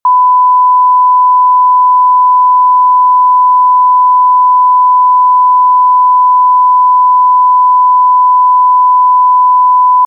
Bạn có thể tải âm thanh hài hước này về để lồng tiếng cho những video hài hước của mình. tiếng chửi hài hước tiếng tức giận tiếng tát vào mặt